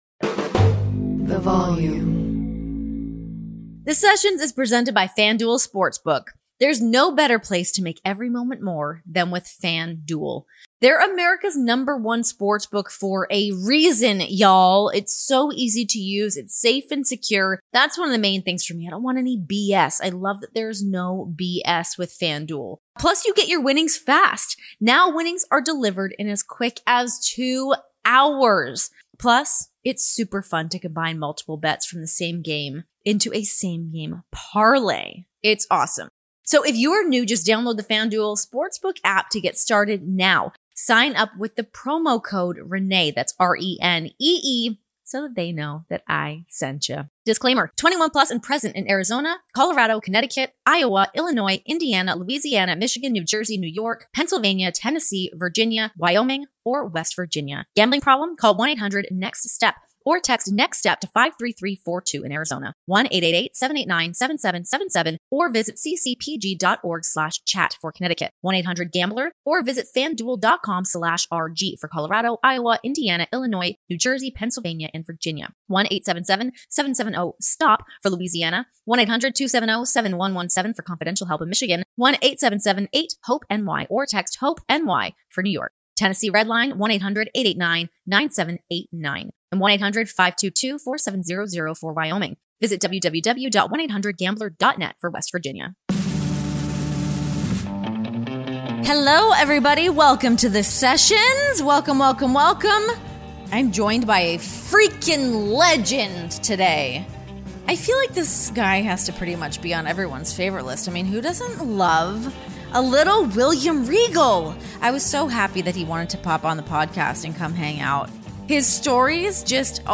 Of all the mentors in the wrestling business, William Regal stands alone. The vaunted veteran, former champion and self-proclaimed “rogue” joins The Sessions for a conversation on his many years in the industry, the change from NXT to NXT 2.0 and his fascinating hobby of colle...